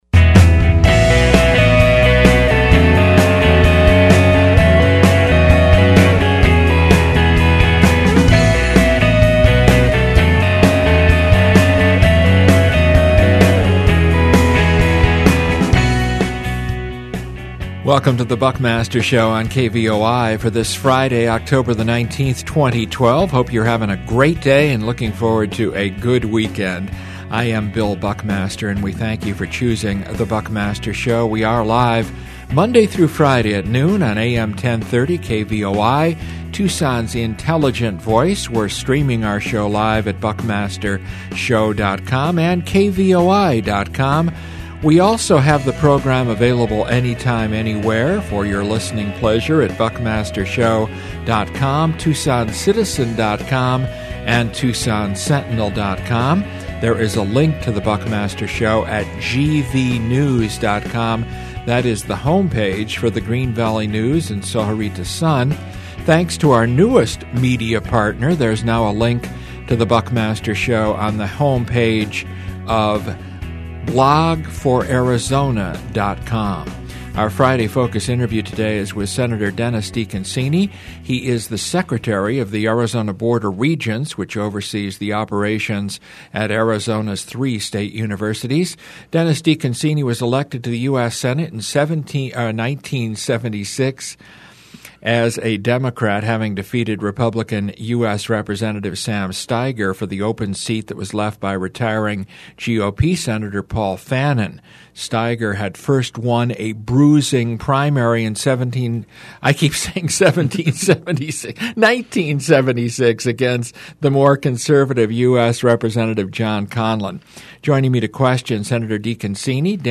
Our Friday Focus interview is with Arizona Board of Regent and former U.S. Senator Dennis DeConcini.